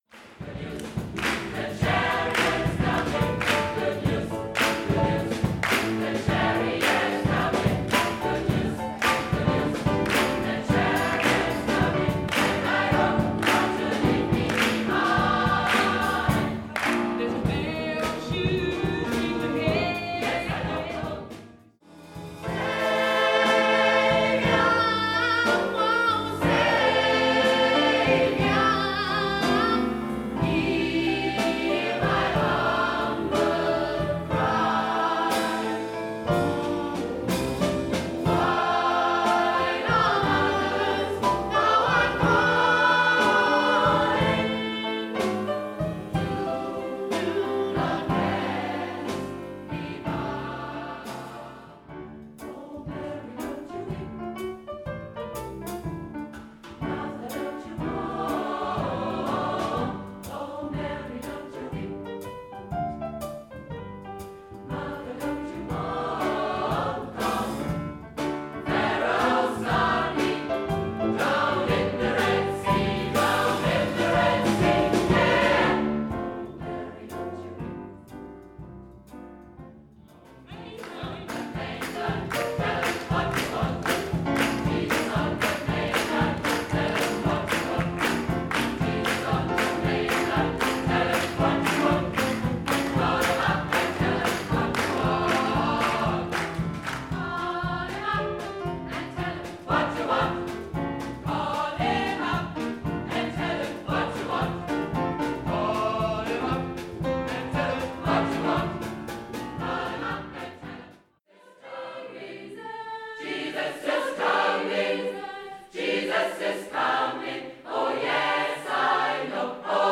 Besetzung: S.A.B.+Pno